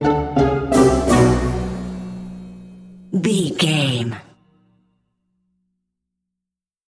Uplifting
Aeolian/Minor
F#
percussion
flutes
piano
orchestra
double bass
silly
goofy
comical
cheerful
perky
Light hearted
quirky